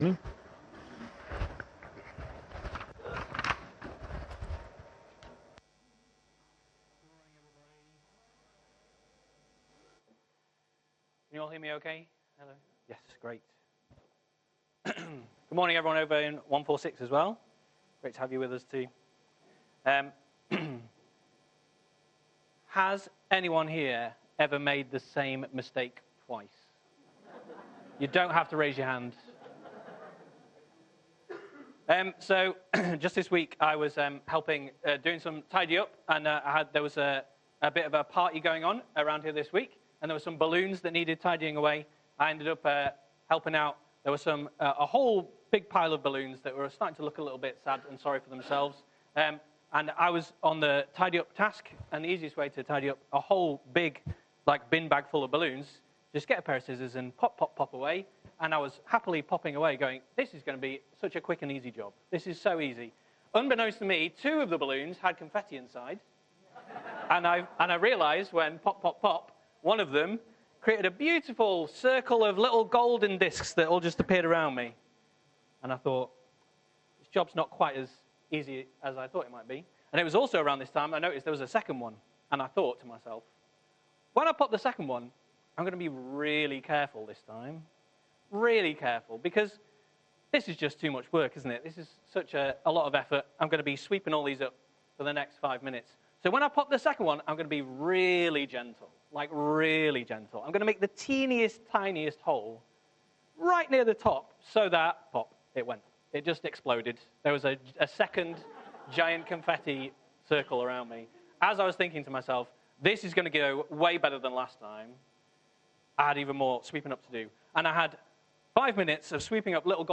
Sermons | Meadowhead Christian Fellowship